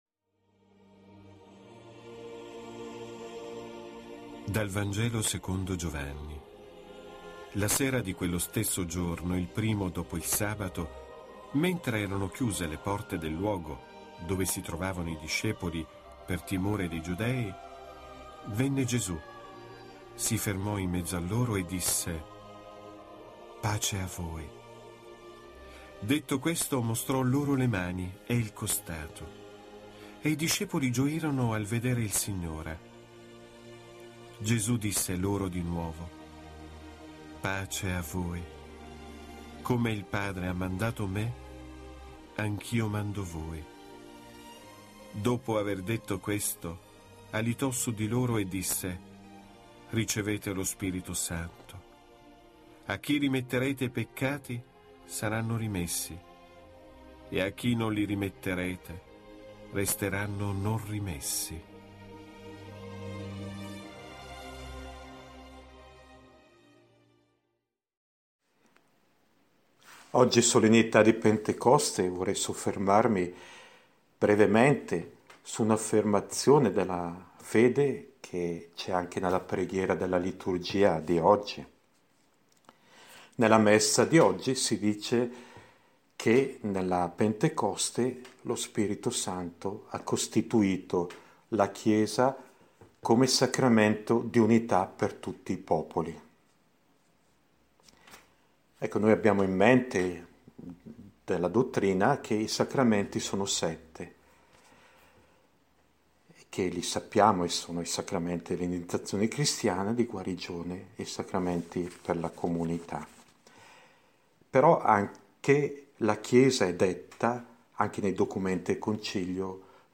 riflessione